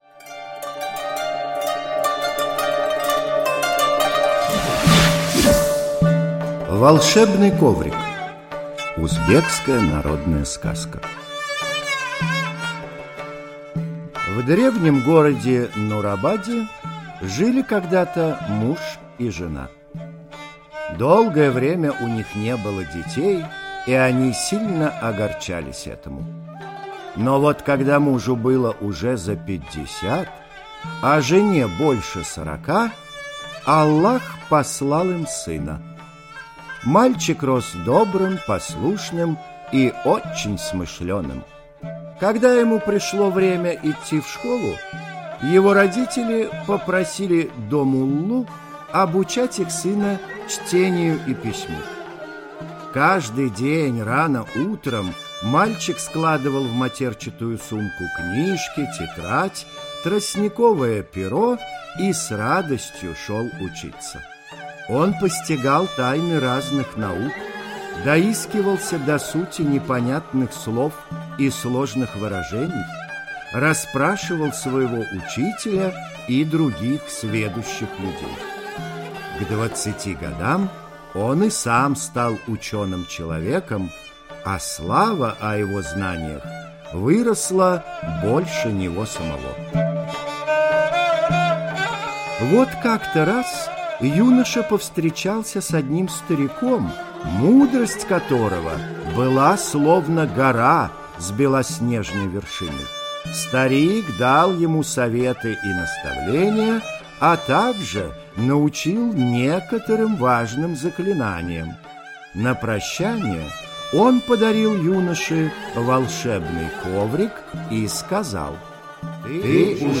Волшебный коврик - узбекская аудиосказка. В древнем городе Нурабаде жили муж, жена и сын.